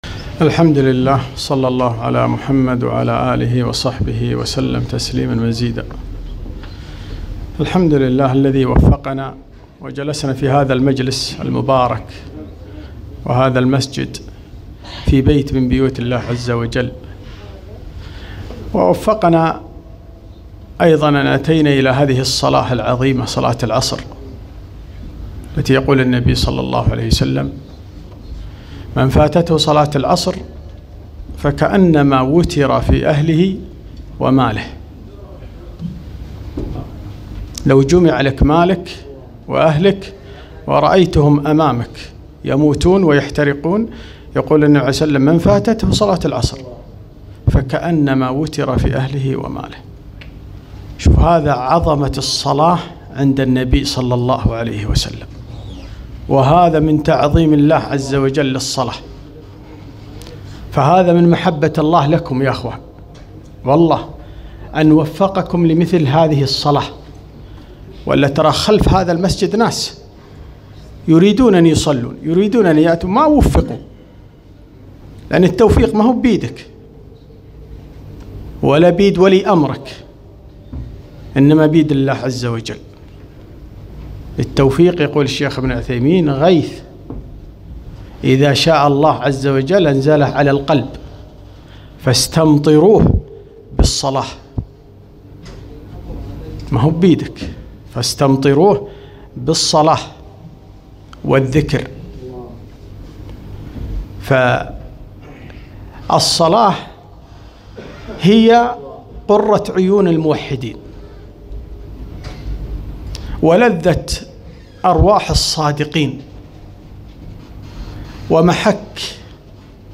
محاضرة نافعة - ( ما يعز عليك من دينك إذا هانت عليك الصلاة )